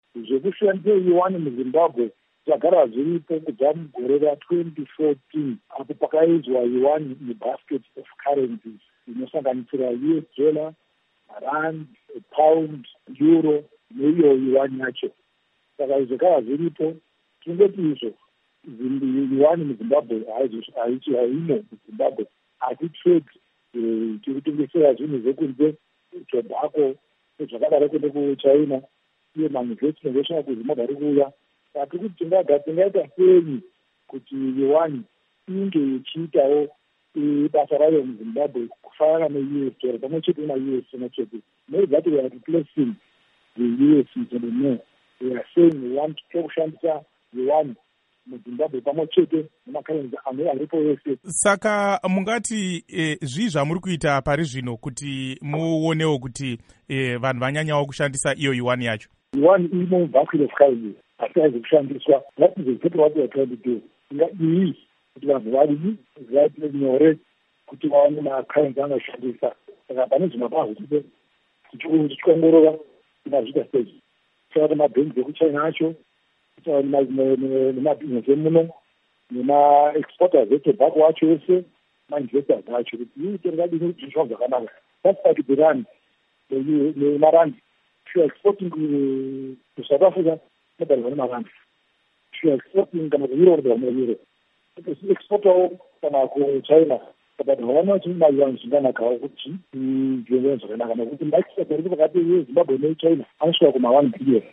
Hurukuro naVaJohn Mangudya